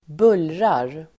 Ladda ner uttalet
bullra verb, make a noise Grammatikkommentar: A/x & Uttal: [²b'ul:rar] Böjningar: bullrade, bullrat, bullra, bullrar Synonymer: dåna, mullra Definition: skapa buller, väsnas Exempel: ett bullrande skratt (a boisterous laugh)